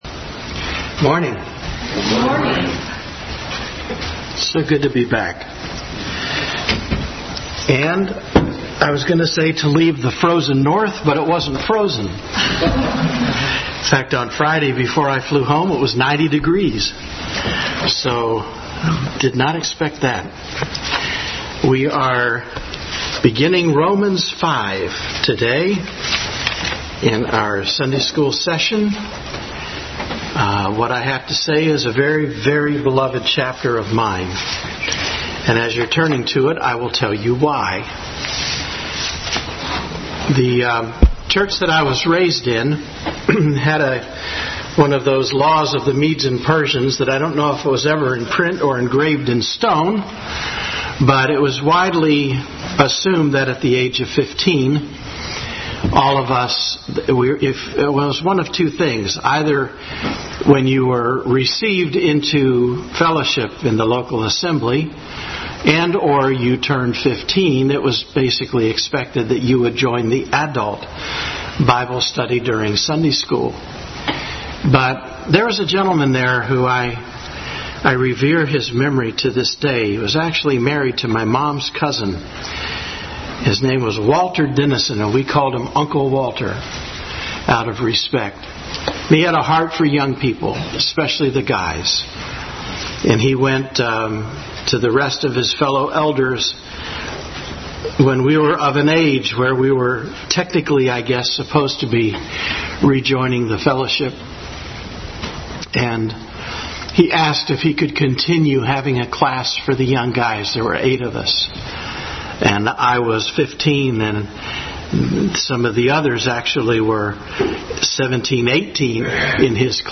Romans 5:1-21 Service Type: Sunday School Bible Text